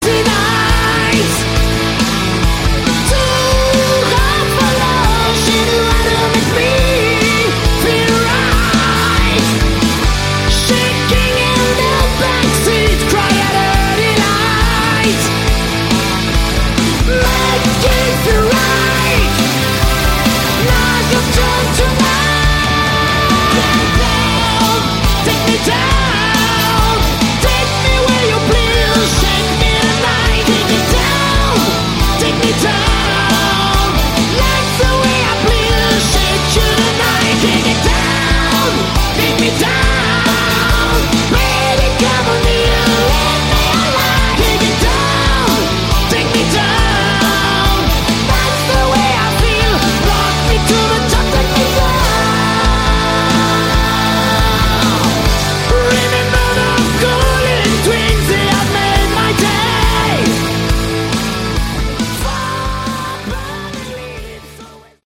Category: Hard Rock
guitar
keyboards
lead vocals
bass
drums